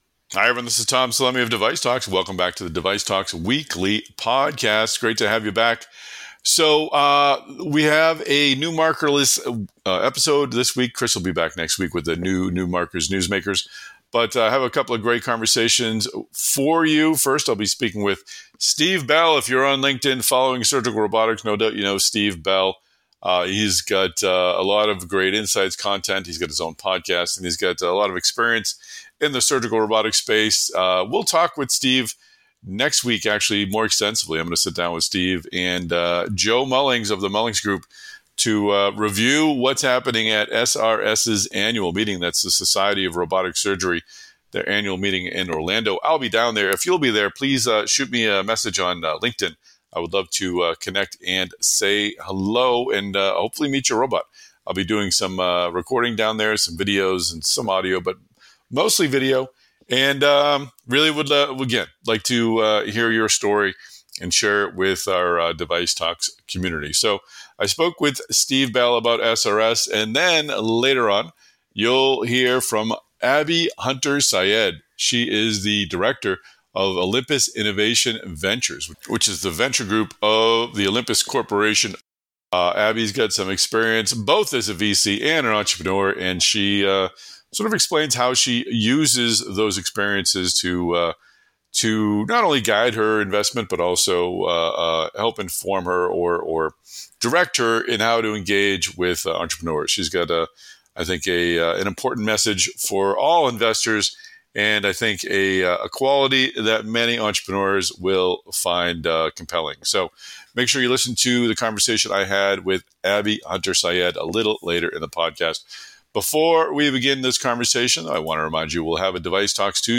In this week’s DeviceTalks Weekly Podcast, we dig deeper into the worlds of Corporate Venture Capital and Surgical Robotics.